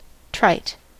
Ääntäminen
IPA : /traɪt/